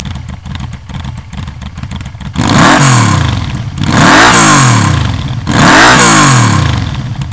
Les grosses sorties sont plus performants, ont un plus beau bruit et sont montés sur les premiers Vmax avant qu'une première vague de limitation du bruit ne vienne frapper.
grosses sorties. Pas les mêmes hein ?